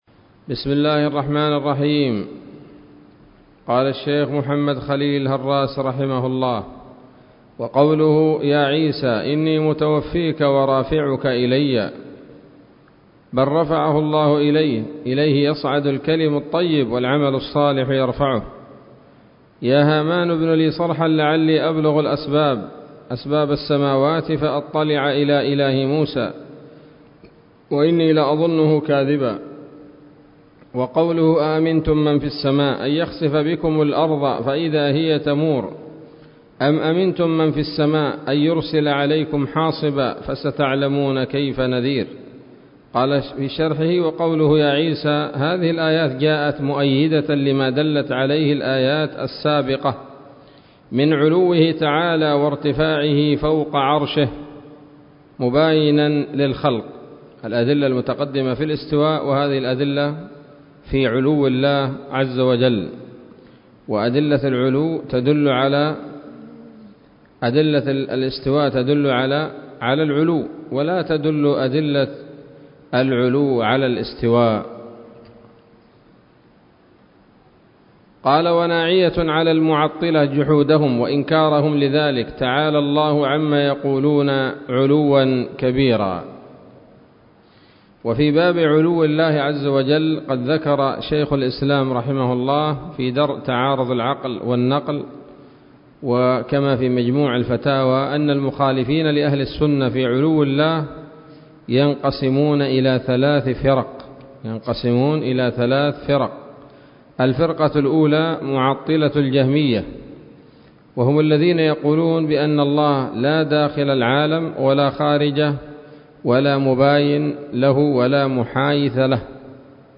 الدرس السادس والسبعون من شرح العقيدة الواسطية للهراس